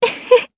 Talisha-Vox-Laugh_Jp.wav